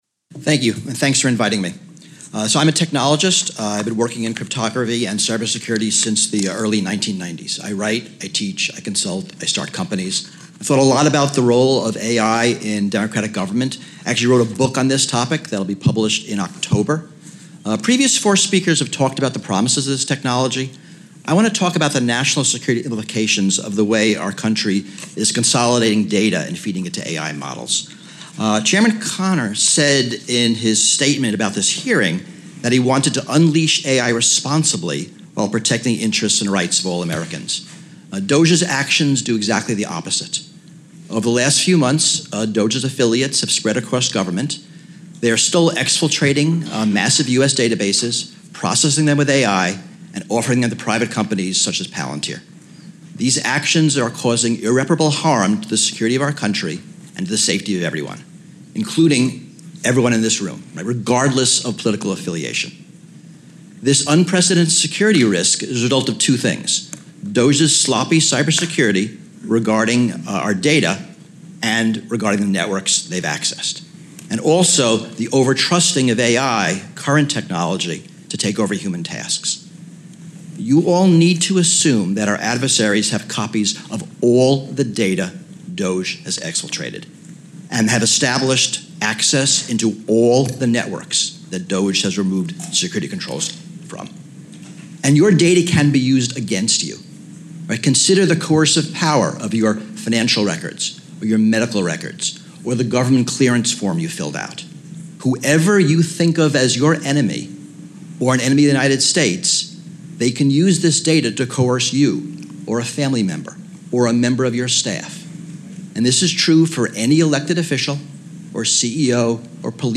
Opening Statement to the House Committee on Oversight and Government Reform Hearing on The Federal Government in the Age of Artificial Intelligence
delivered 5 June 2025, Washington, D.C.
Audio Note: AR-XE = American Rhetoric Extreme Enhancement